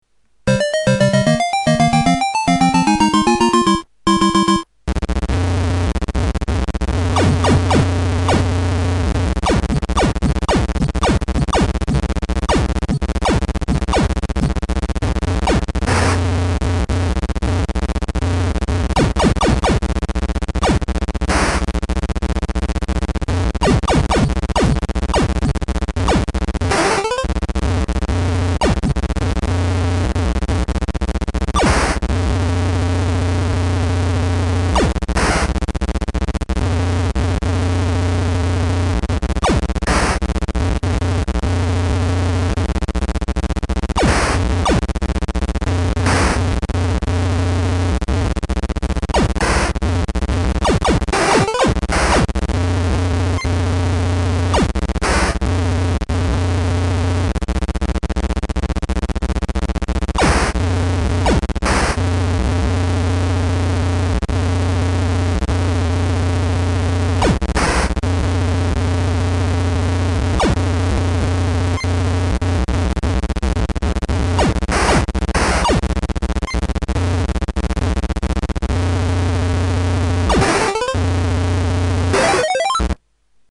Двигатель танчика